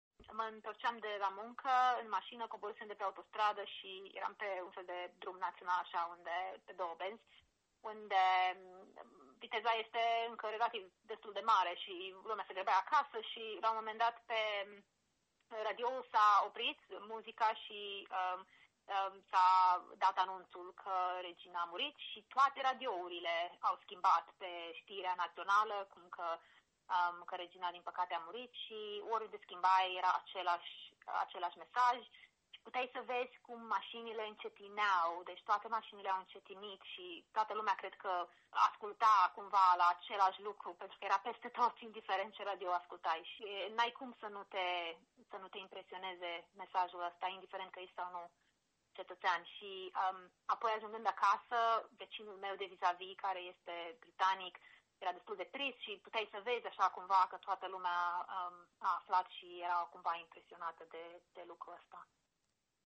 O româncă stabilită la Londra a povestit la Europa FM cum au primit britanicii vestea morții reginei: